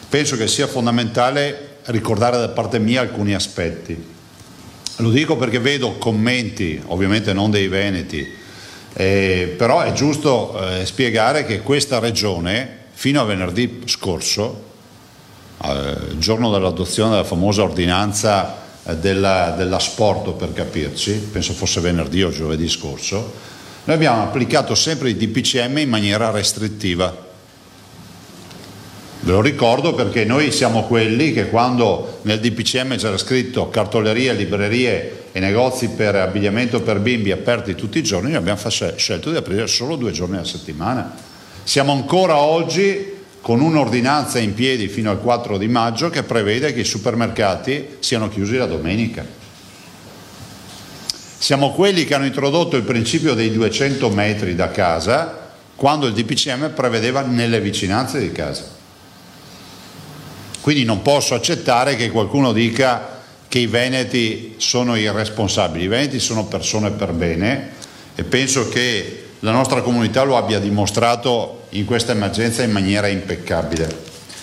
I VENETI NON SONO IRRESPONSABILI…DALLA CONFERENZA STAMPA DI ZAIA OGGI